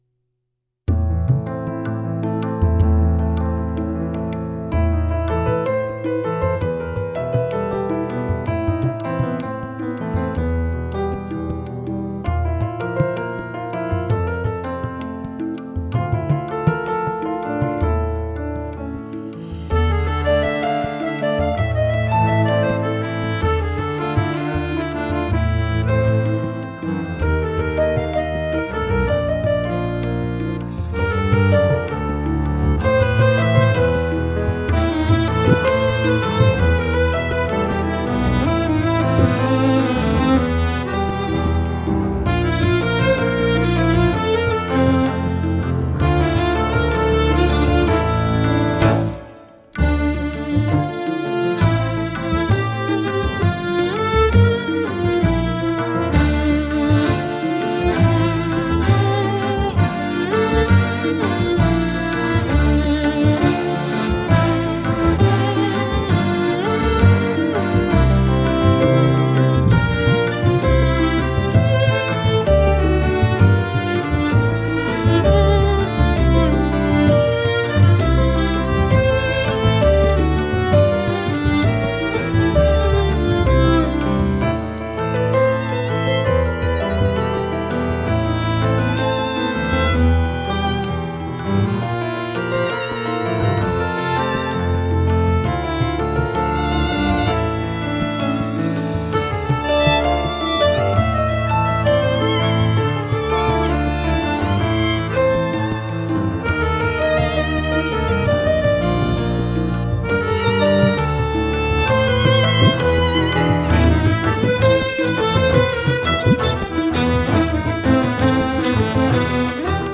Genre: Jazz, World, Accordion